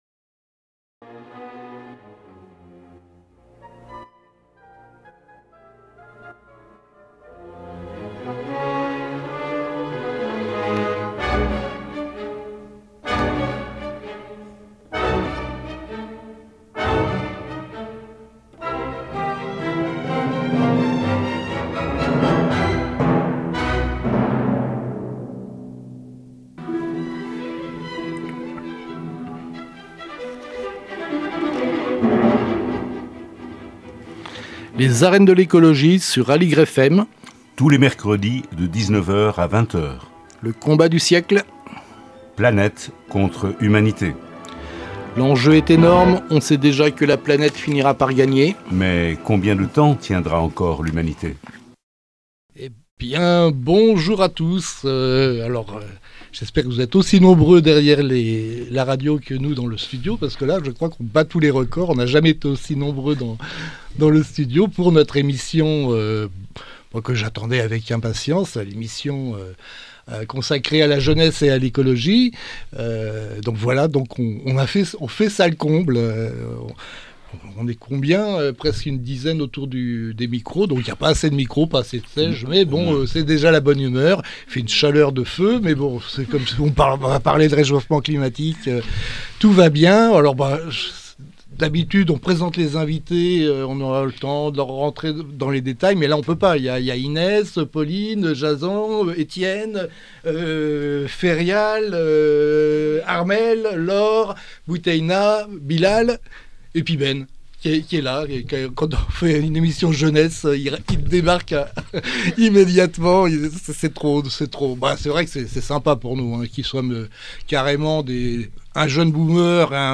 Avec un mois d'avance sur la semaine thématique Jeunesse d'Aligre FM, les Arènes de l'écologie proposent la première rencontre intergénérationnelle à l'antenne.